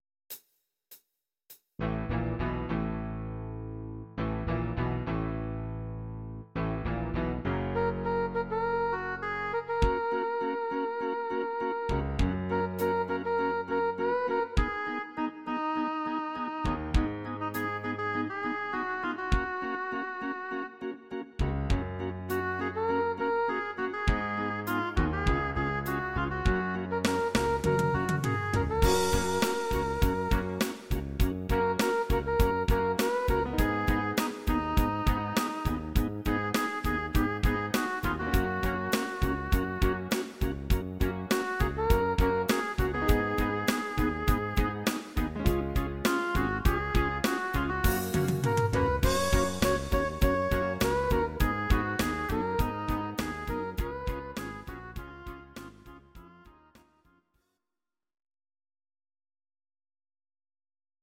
Audio Recordings based on Midi-files
Pop, 1970s